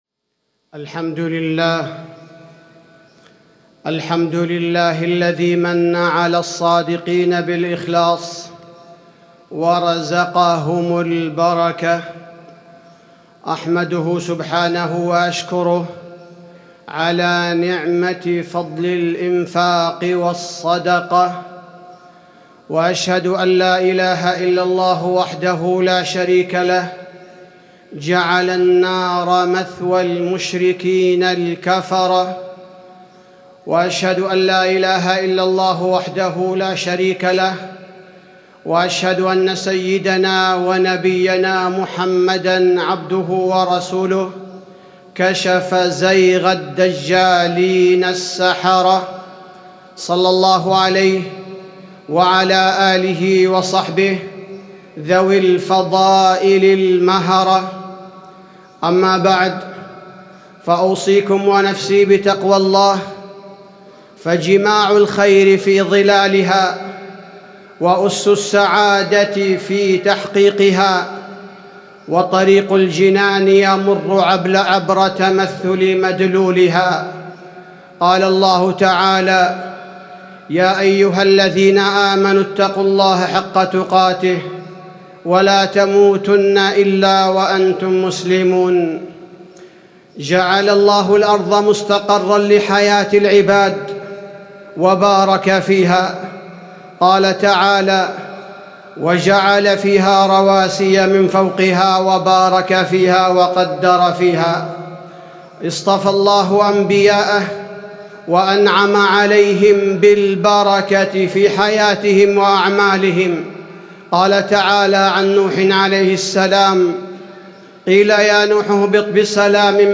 تاريخ النشر ٢ جمادى الأولى ١٤٣٩ هـ المكان: المسجد النبوي الشيخ: فضيلة الشيخ عبدالباري الثبيتي فضيلة الشيخ عبدالباري الثبيتي البركة في حياة المسلم